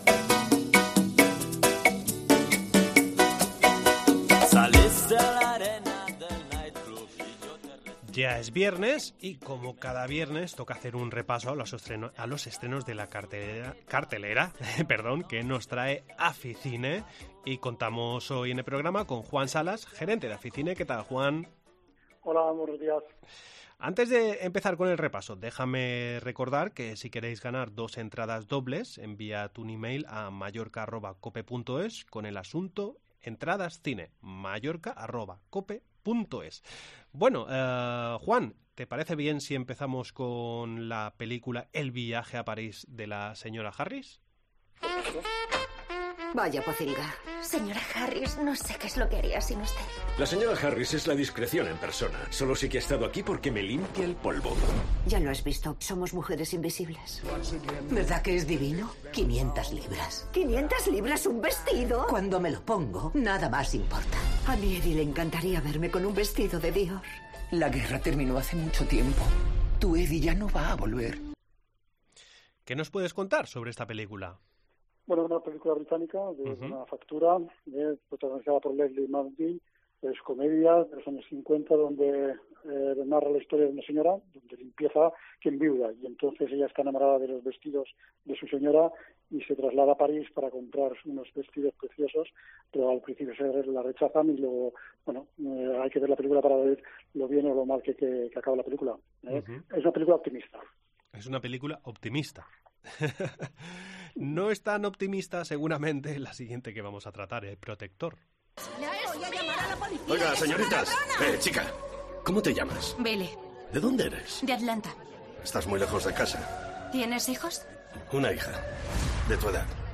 Entrevista en La Mañana en COPE Más Mallorca, viernes 09 de diciembre de 2022.